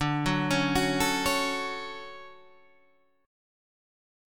D Major 7th